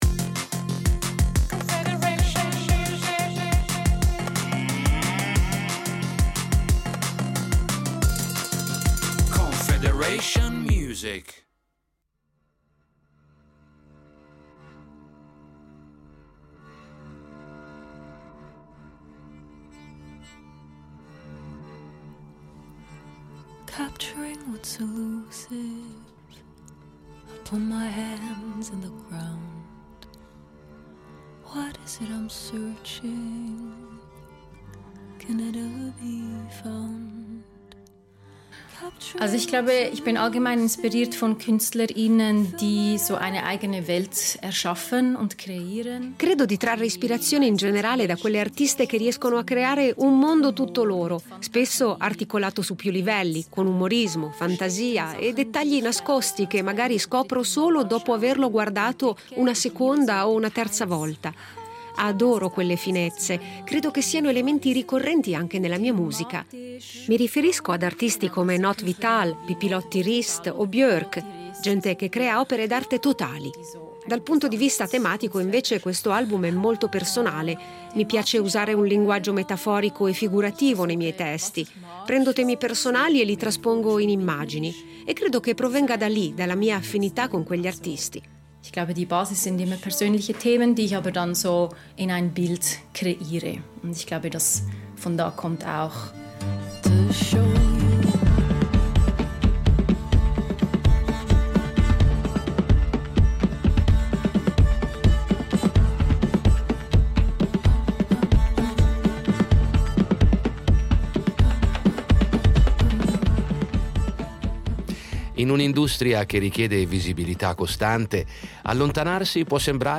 Musica pop